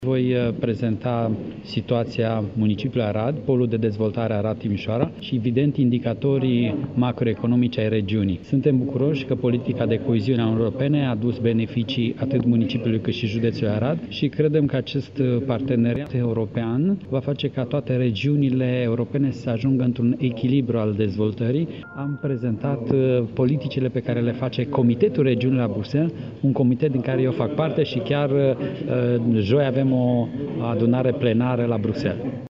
Diplomaţi şi şefi ai regiunilor din Europa se află zilele acestea la Arad, la Plenara de Primăvară a Adunării Regiunilor Europei
Autorităţile locale le prezintă oaspeţilor europeni atuurile Aradului. Primarul Gheorghe Falcă spune că va prezenta polul de dezvoltare Arad – Timișoara și indicatorii macroeconomici ai regiunii.